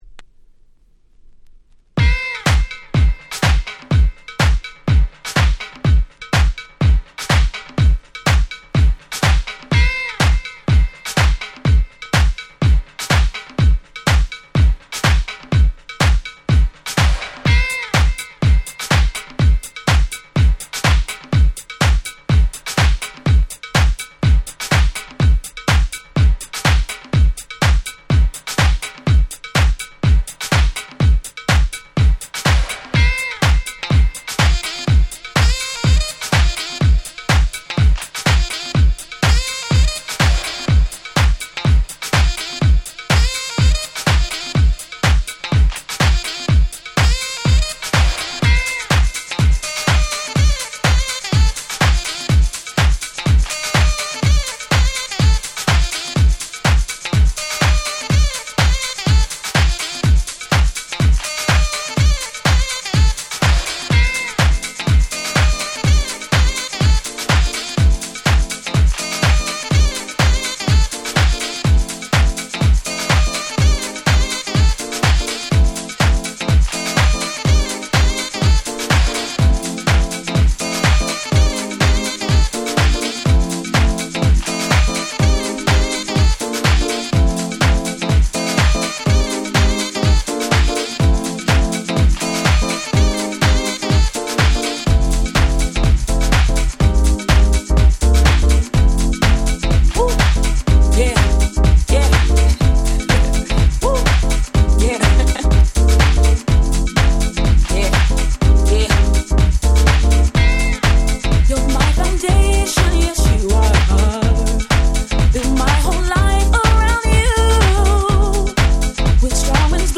01' Nice Vocal House / R&B !!
爽快な女性Vocal House。